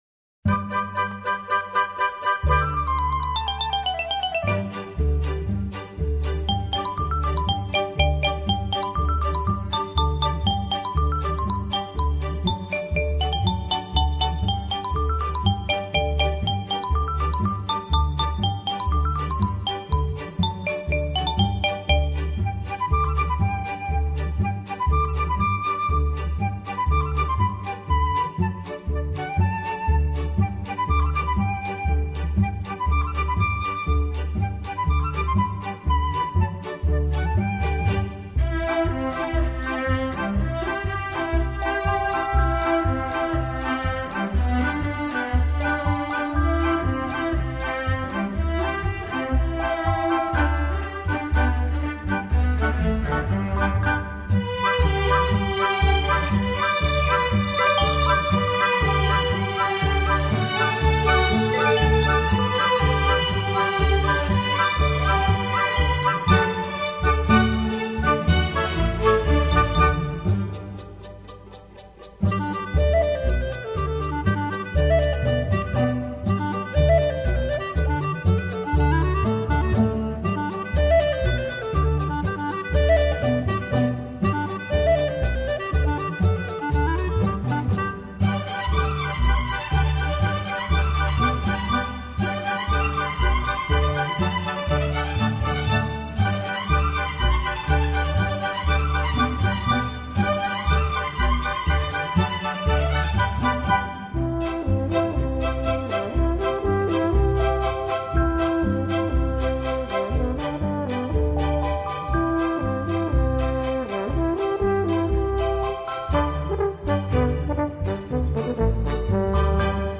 整體風格節奏分明、鏗鏘有力、充滿活力，